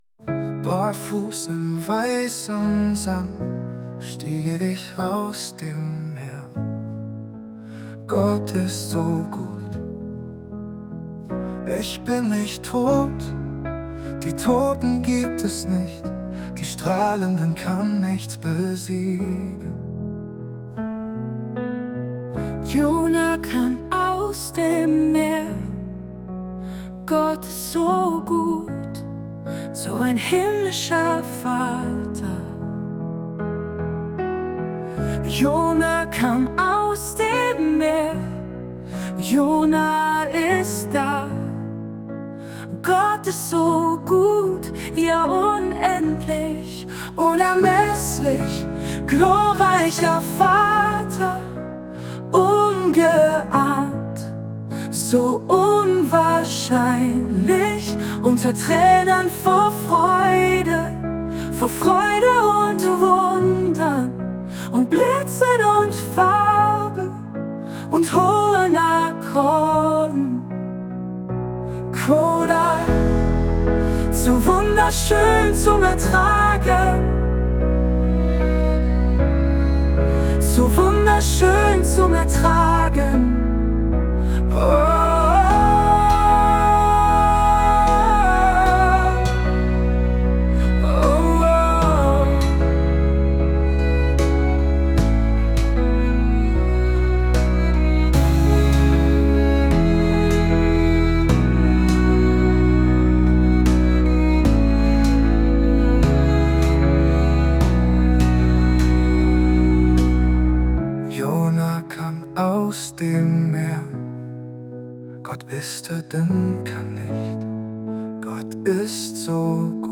Natürlich hätte man auch die KI selbst einen Liedtext schreiben lassen:
Das Ergebnis ist interessant aber klingt etwas zufällig.